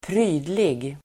Uttal: [²pr'y:dlig]